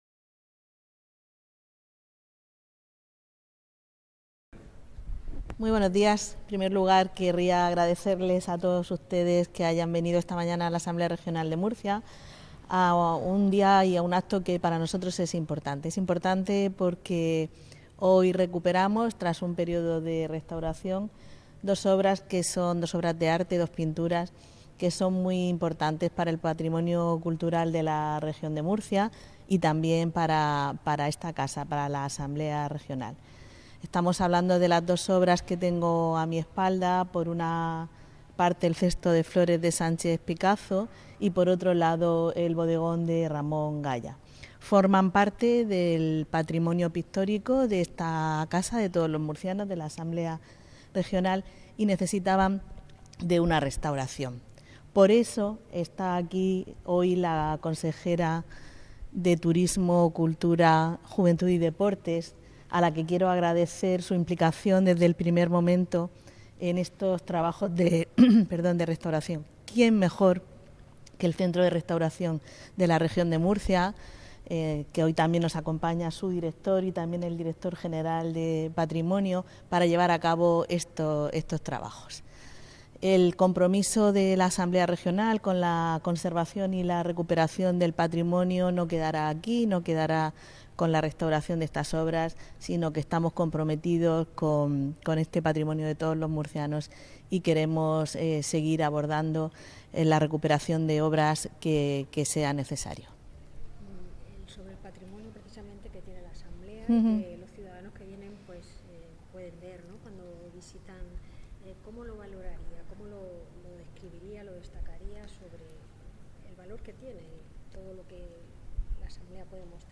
• Declaraciones de la presidenta de la Asamblea Regional y de la consejera de Turismo, Cultura, Juventud y Deportes